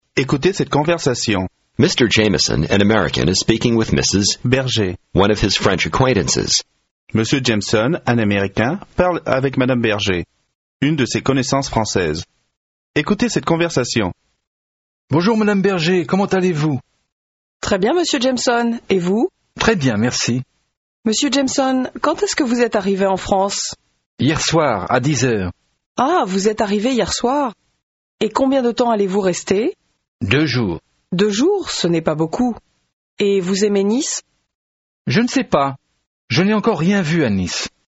Аудио курс для самостоятельного изучения французского языка.